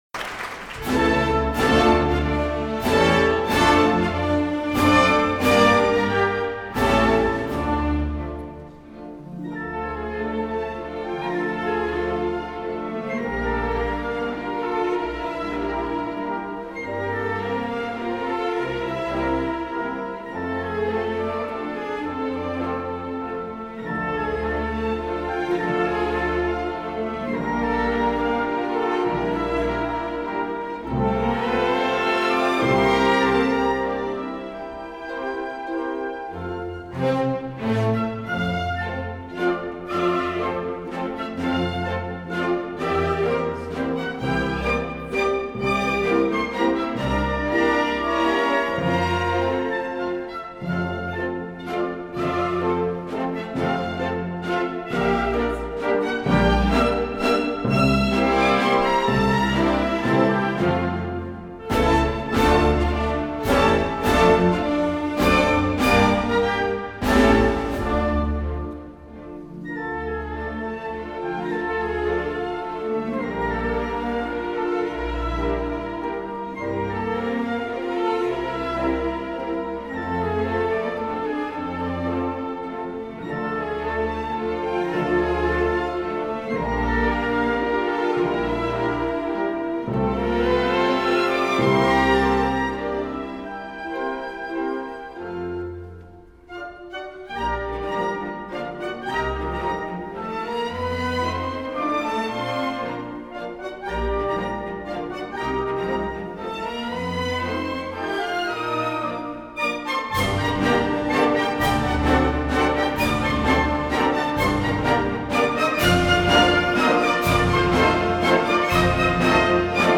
Polka Mazur op.330